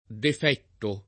defetto [ def $ tto ]